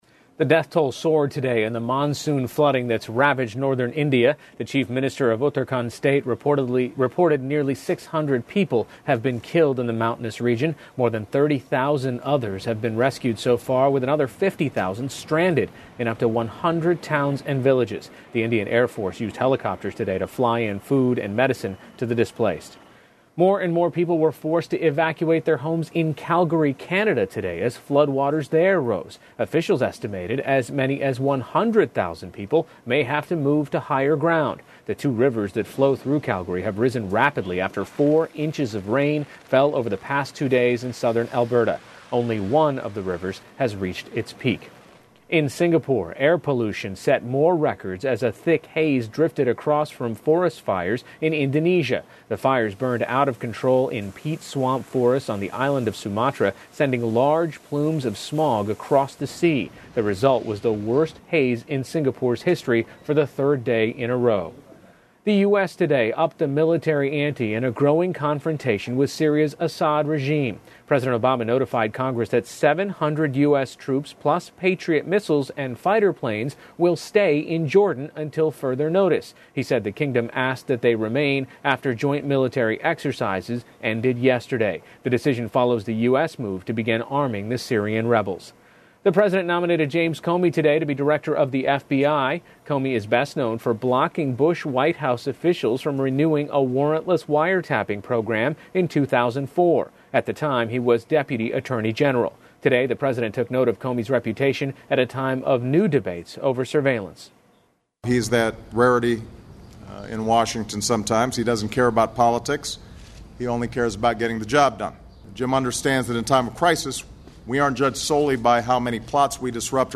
pbs高端访谈:印度东北部洪灾严重 听力文件下载—在线英语听力室